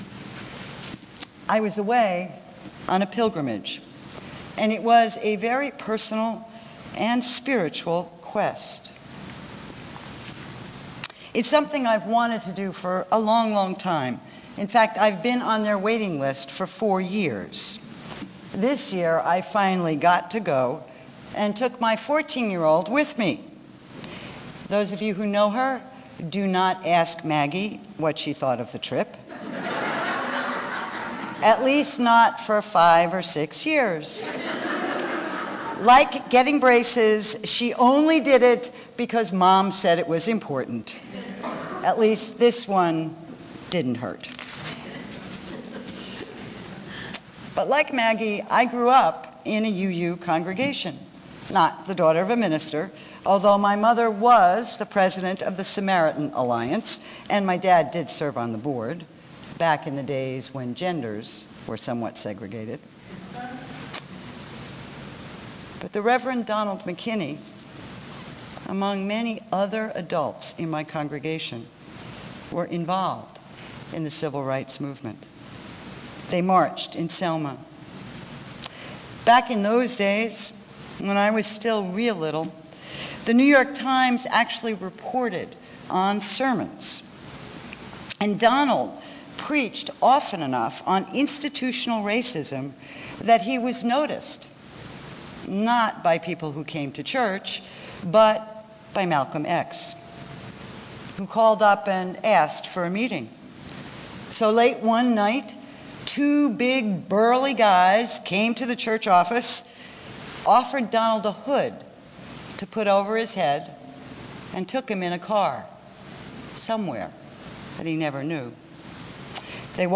by | Sep 29, 2013 | Recorded Sermons | 0 comments